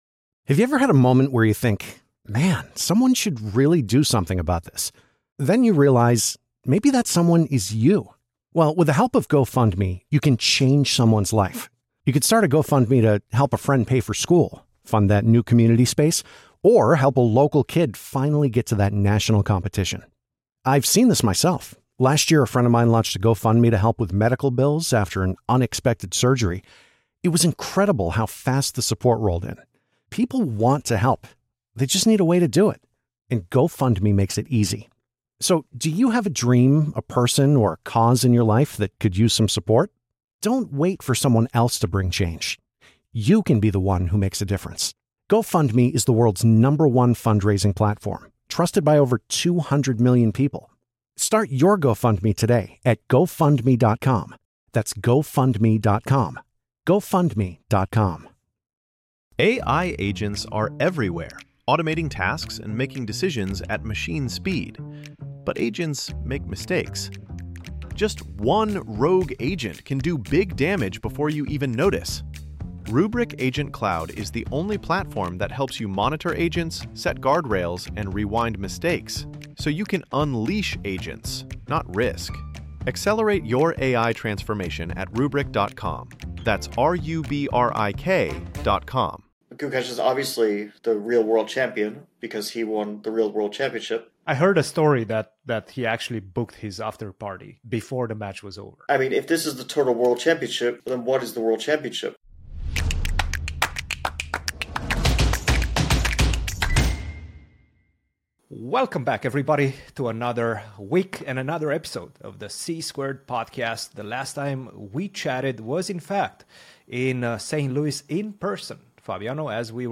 The World Cup is one of the most exciting events of the chess calendar, and this year's edition has not disappointed. The boys get together to chat about the favorites, give their predictions, and discuss the big surprises of the first rounds.